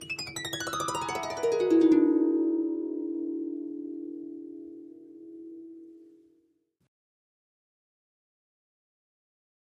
Harp, Descending Gliss, 7th Chord, Type 1 - Long Sustain